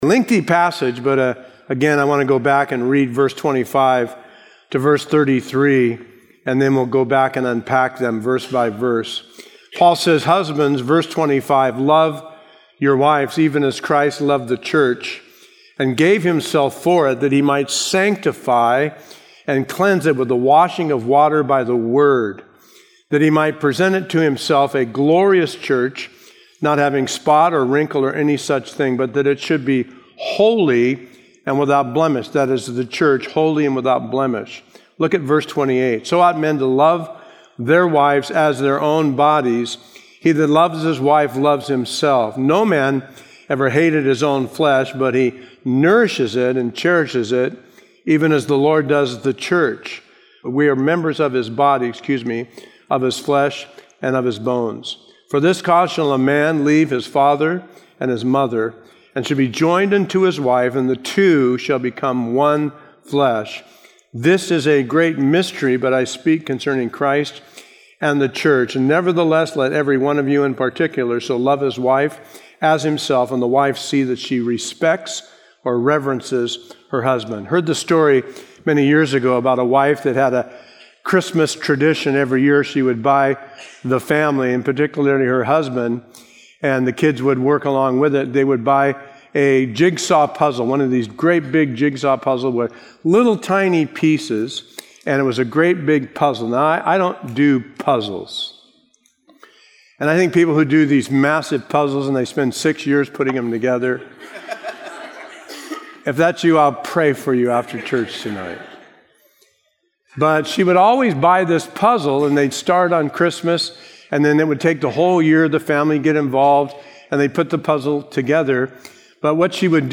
A verse-by-verse sermon through Ephesians 5:22-33